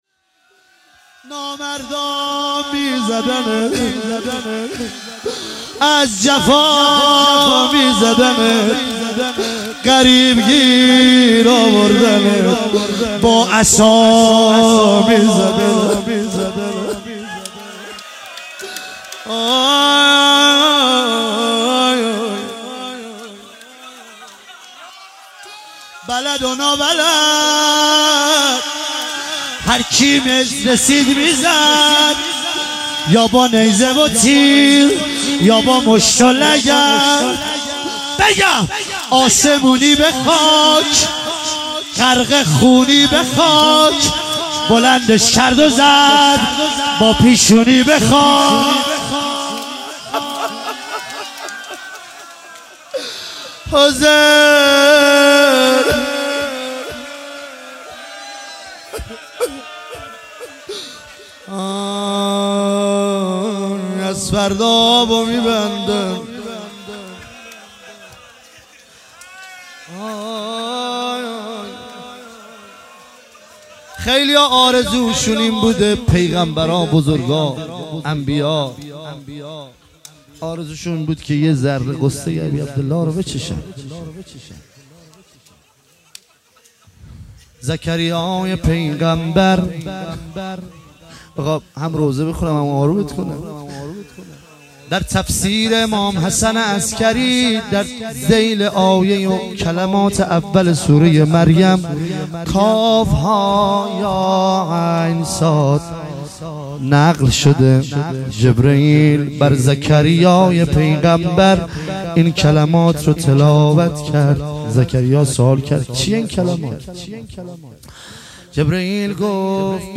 مراسم شب هفتم محرم 1397 هیئت الشهدا آزادشهر
07-Roze-payani-shabe-7-moharram-97.mp3